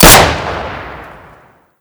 shoot2.ogg